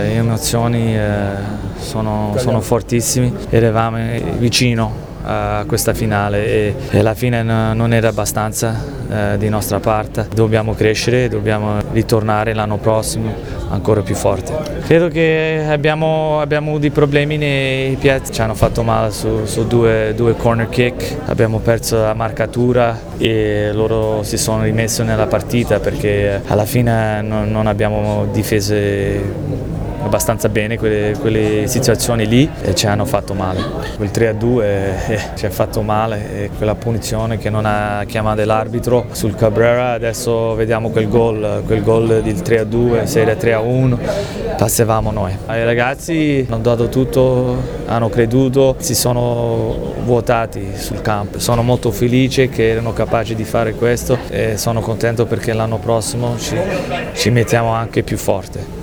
Le interviste del postpartita –